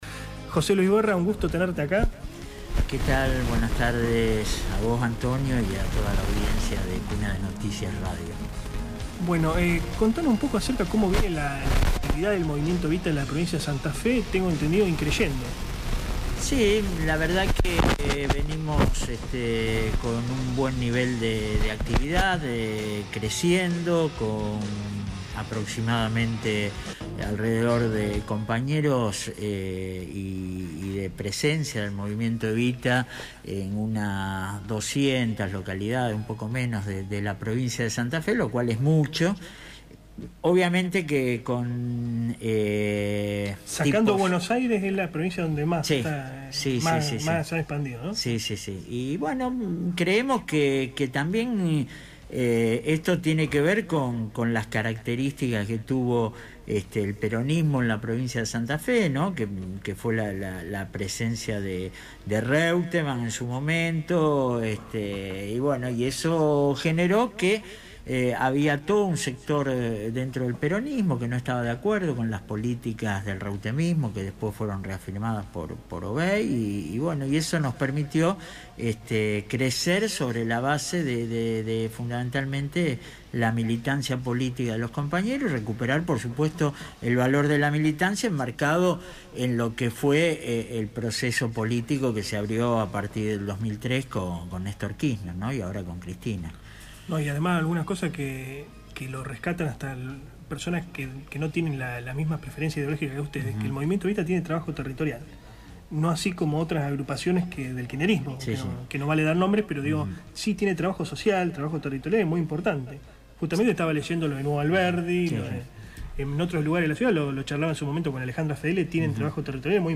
AUDIO ENTREVISTA